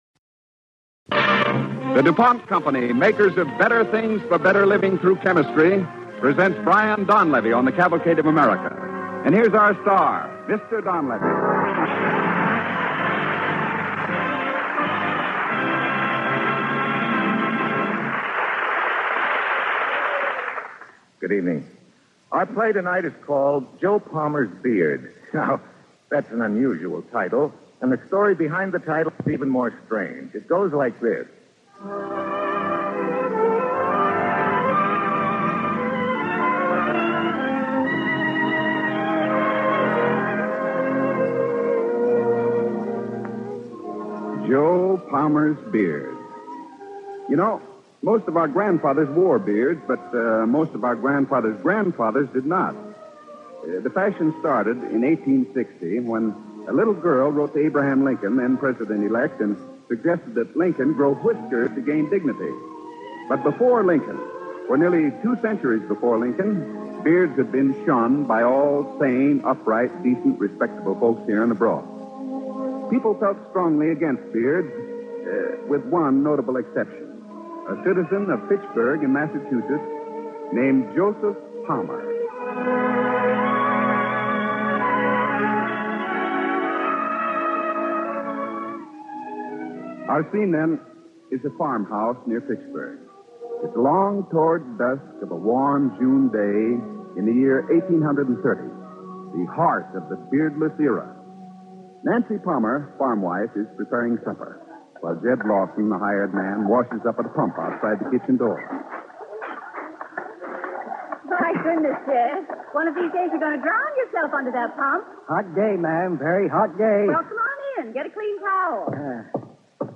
Joe Palmer's Beard, starring Brian Donlevy